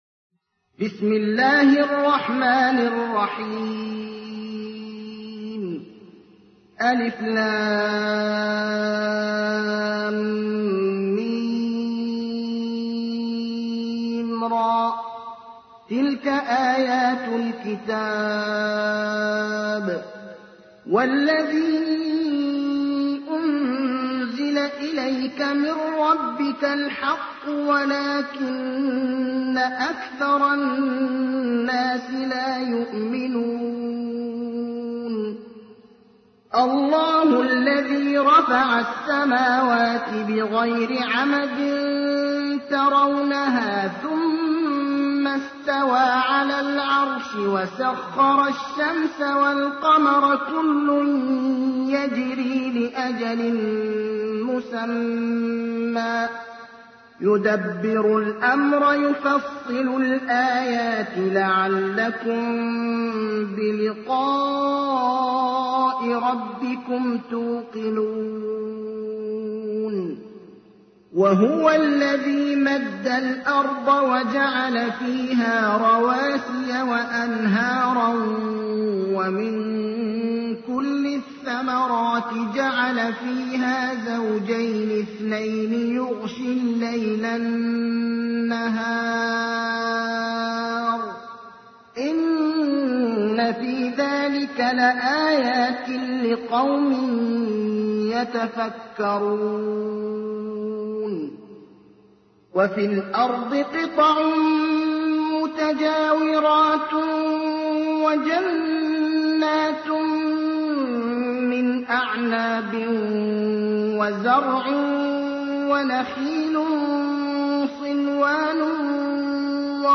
تحميل : 13. سورة الرعد / القارئ ابراهيم الأخضر / القرآن الكريم / موقع يا حسين